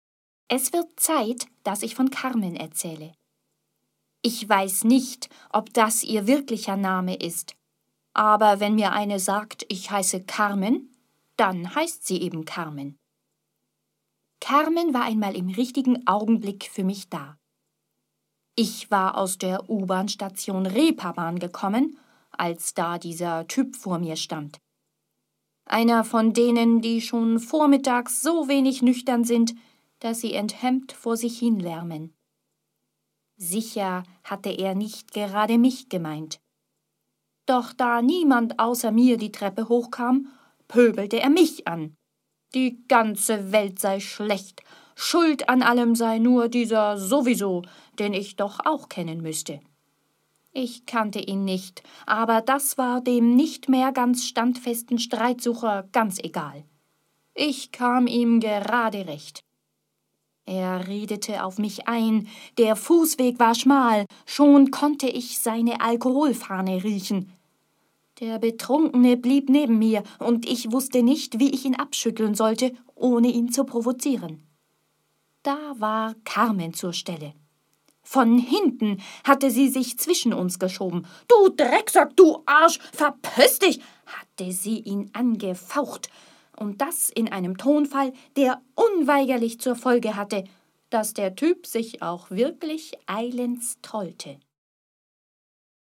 Hier sind einige Beispiele, wie es klingt, wenn ich aus meinen Texten etwas vorlese.